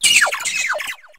wiglett_ambient.ogg